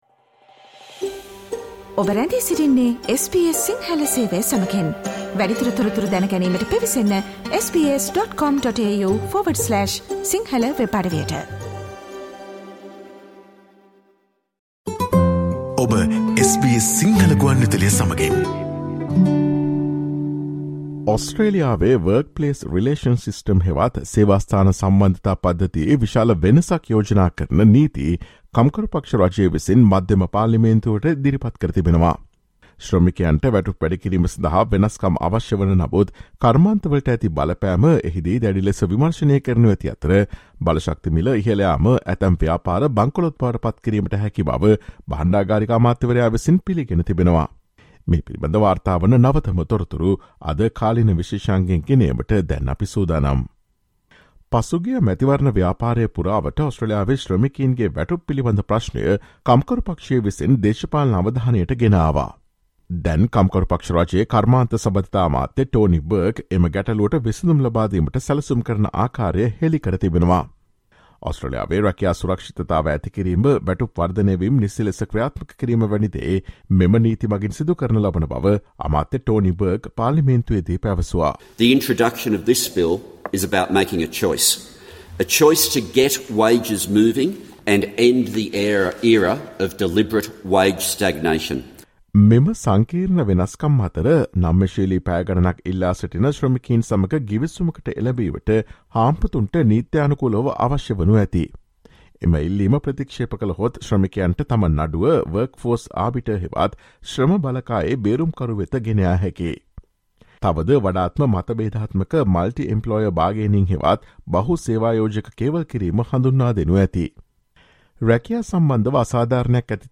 Labor says the changes are needed to secure a pay rise for workers but the impact on the industry will be heavily scrutinised, with the Treasurer conceding soaring energy prices could send some businesses to the brink. Listen to the SBS Sinhala Radio's current affairs feature broadcast on Friday, 28 October.